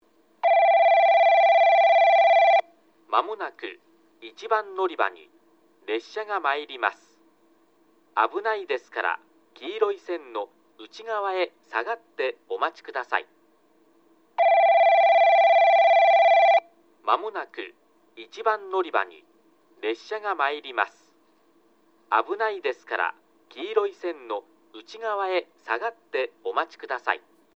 放送は上下とも1，3番のりばが男声、2，4番のりばが女声で固定されています。スピーカーは旧放送同様TOAラッパ型から流れ、クリアホーンからは遠隔放送が流れます。
1番のりば接近放送　男声